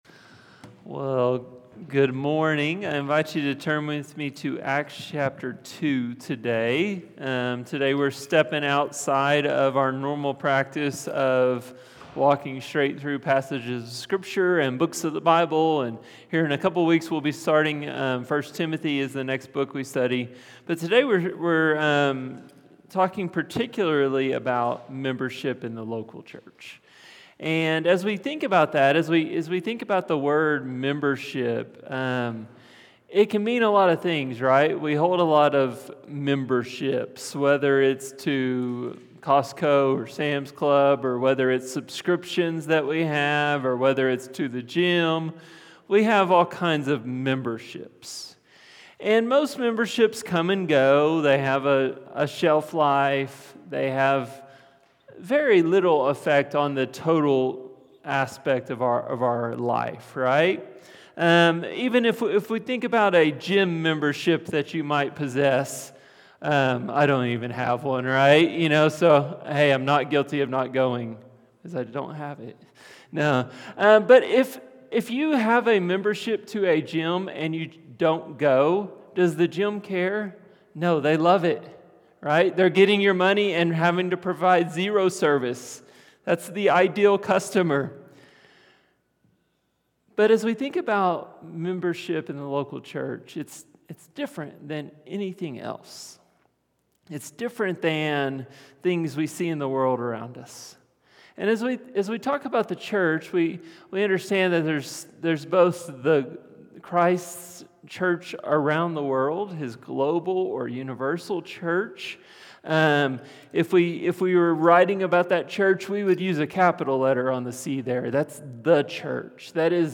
From Series: "Stand-Alone Sermons"